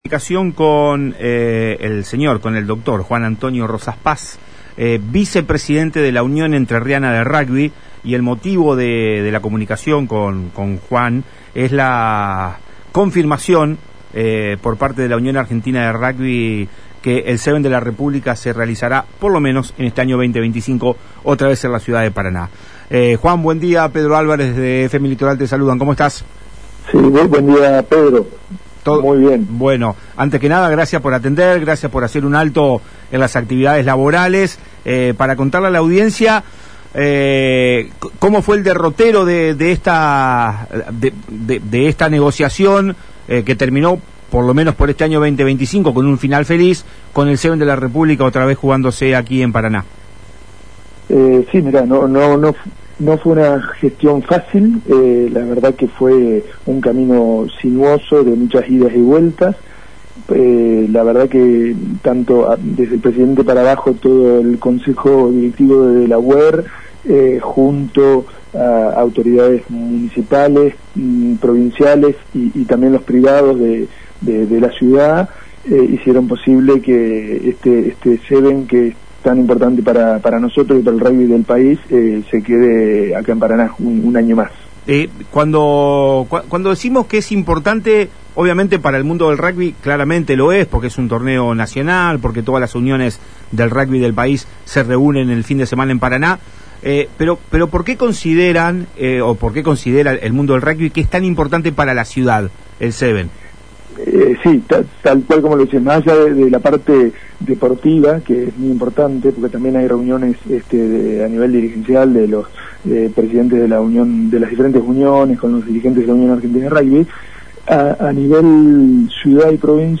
en una entrevista exclusiva con el programa Palabras Cruzadasd por FM Litoral.